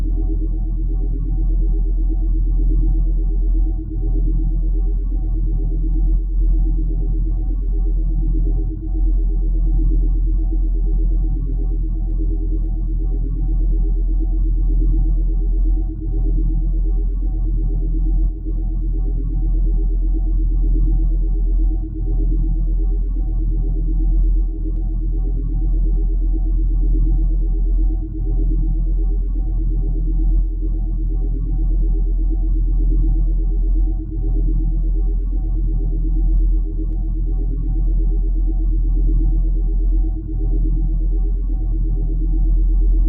sphere hum edit.wav